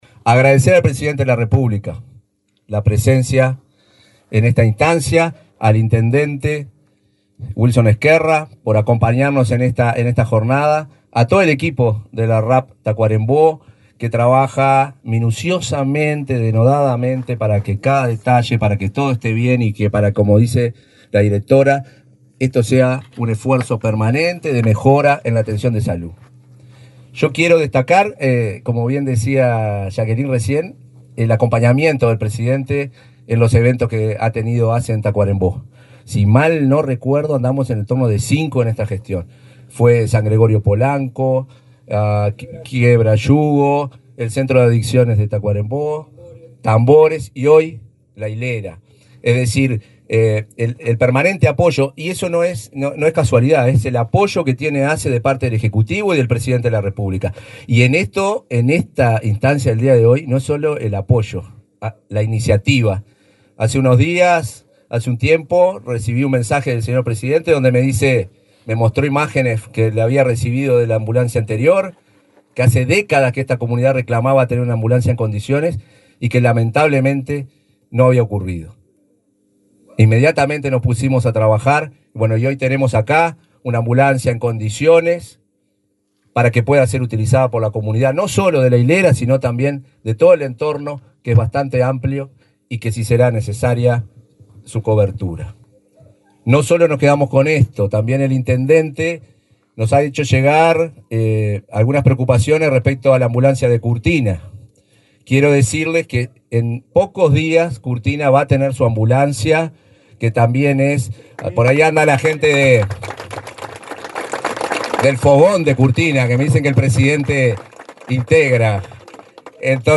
Palabras del presidente de ASSE, Marcelo Sosa
Palabras del presidente de ASSE, Marcelo Sosa 02/09/2024 Compartir Facebook X Copiar enlace WhatsApp LinkedIn El presidente de ASSE, Marcelo Sosa, entregó, este lunes 2, una ambulancia a la policlínica de la localidad de La Hilera, en el departamento de Tacuarembó.